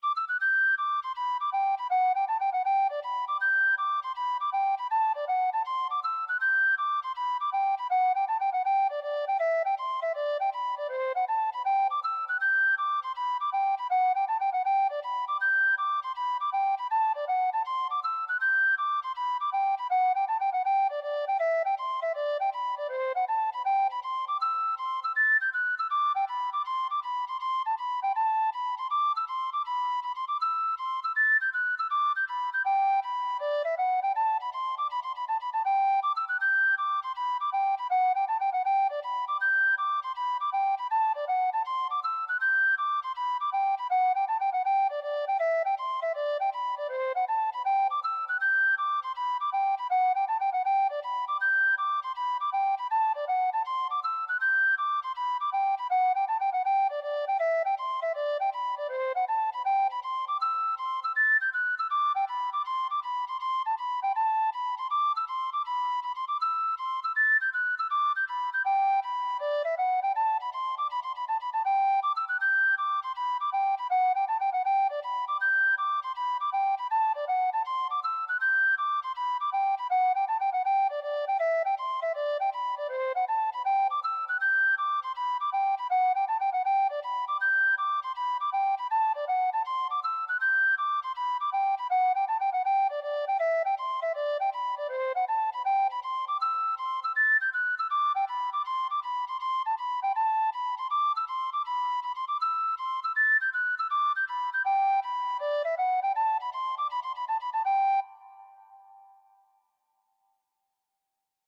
Hornpipe
R: Hornpipe
M: 4/4
K: Gmaj